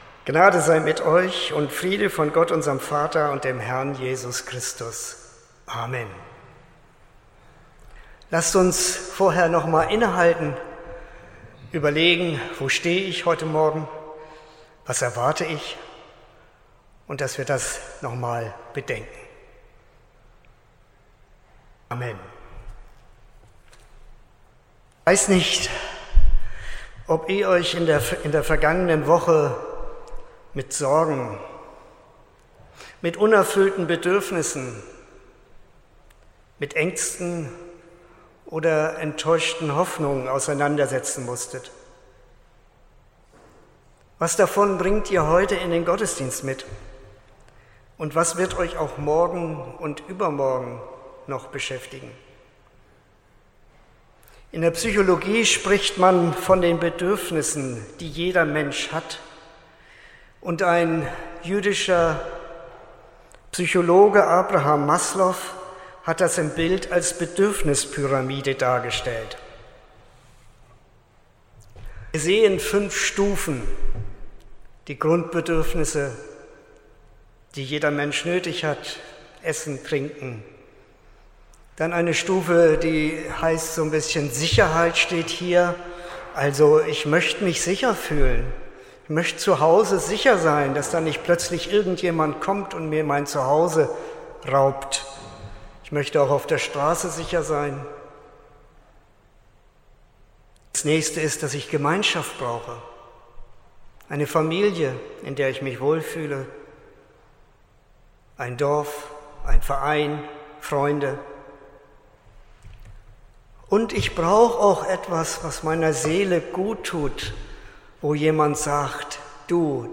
14.07.2024 – Gottesdienst
Predigt (Audio): 2024-07-14_Gott_versorgt_uns.mp3 (12,8 MB)